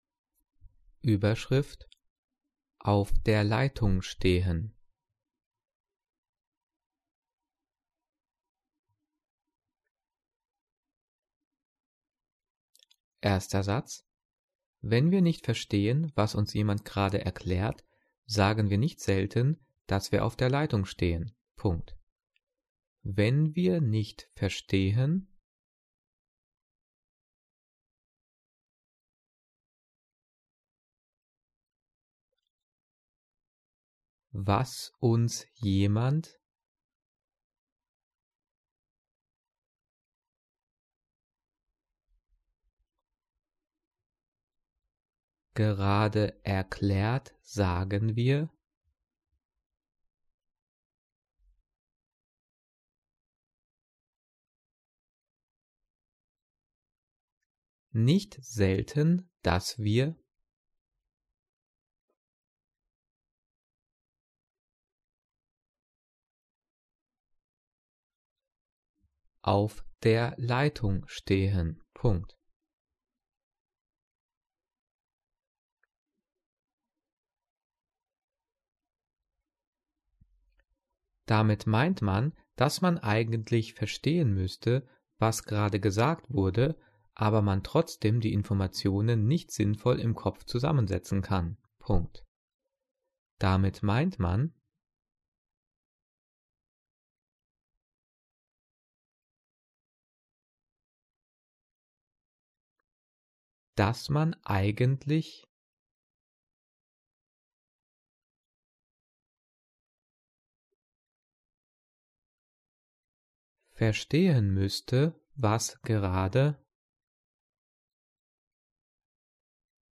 Übungsdiktat 'Auf der Leitung stehen' für die 7. und 8. Klasse zum Thema Zeichensetzung diktiert und mit Lösung.
Die vielen Sprechpausen sind dafür da, dass du die Audio-Datei pausierst, um mitzukommen.